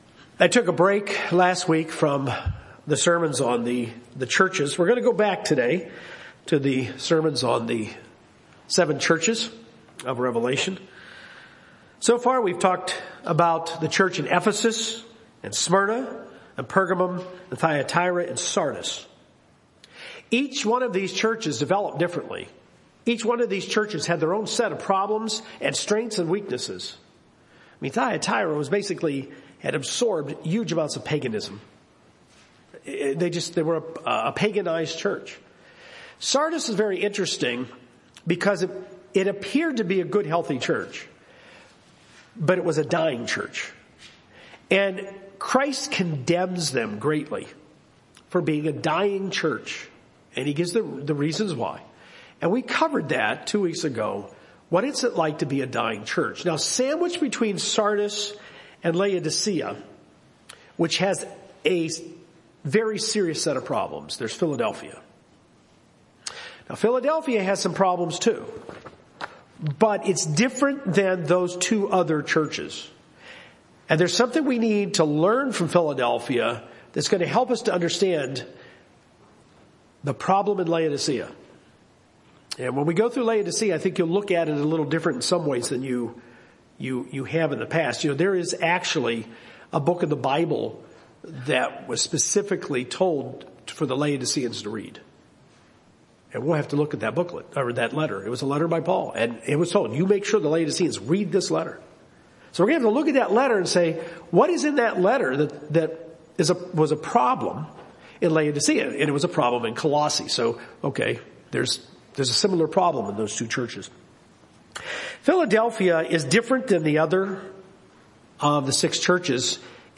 The Church in Philadelphia is commended by Jesus for persevering in their faith, and for not denying His lordship over them. This sermon explores what the key of David is, what the door that Jesus has opened is a reference to, and three ways we can deny His lordship over us so we can be on guard against it.